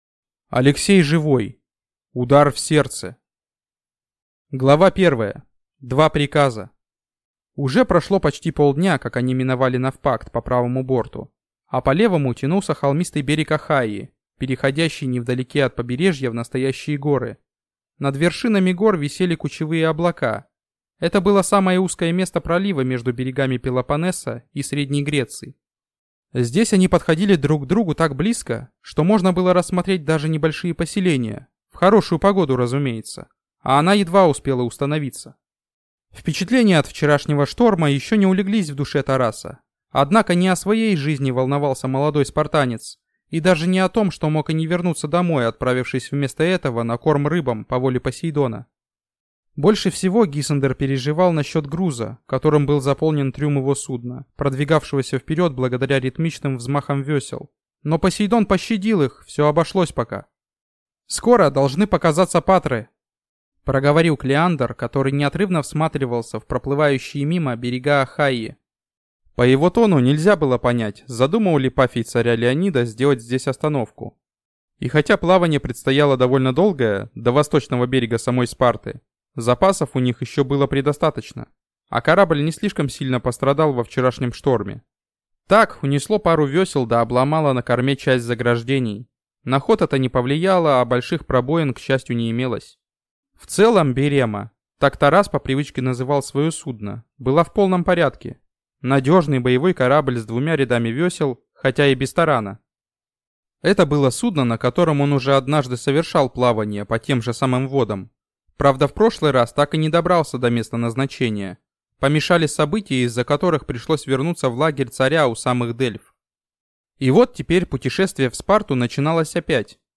Аудиокнига Удар в сердце | Библиотека аудиокниг